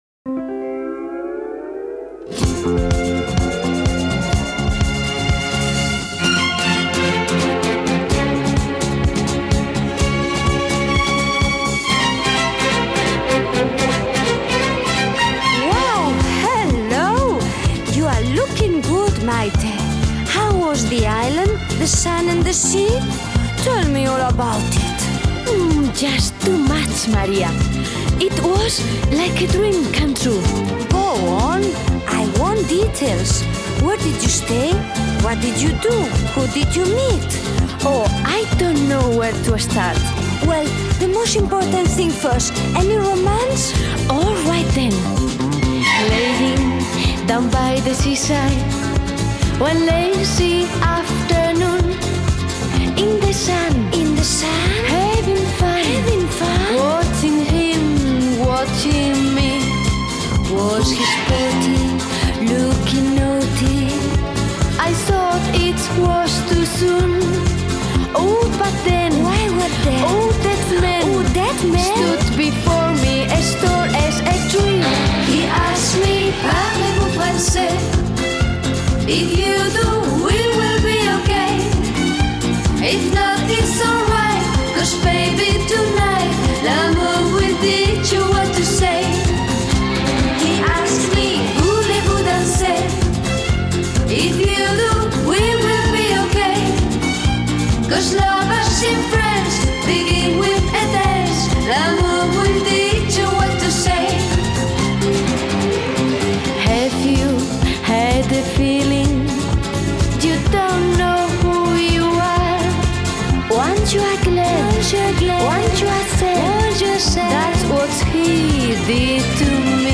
Genre: Pop Rock, Synth-pop, Disco